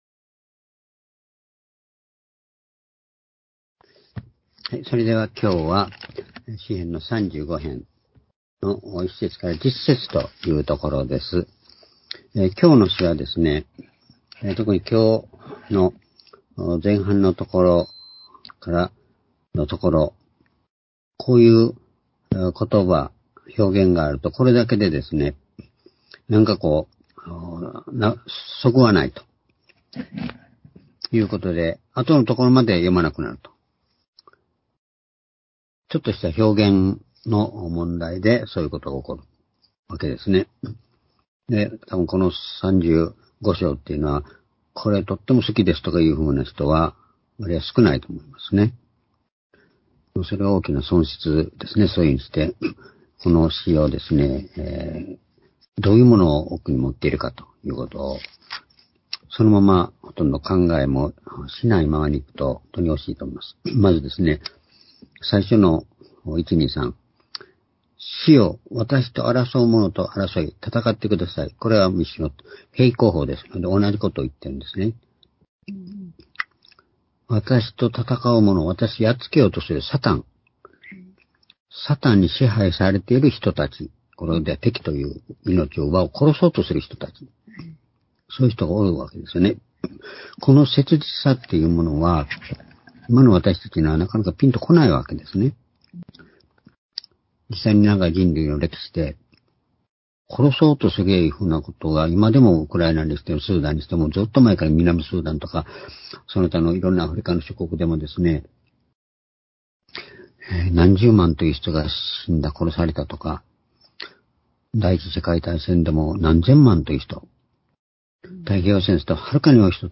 （主日・夕拝）礼拝日時 ２０２３年5月16日（夕拝） 聖書講話箇所 「「サタンとの戦いと勝利」 詩篇35篇1節～10節 ※視聴できない場合は をクリックしてください。